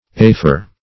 afer - definition of afer - synonyms, pronunciation, spelling from Free Dictionary Search Result for " afer" : The Collaborative International Dictionary of English v.0.48: Afer \A"fer\, n. [L.]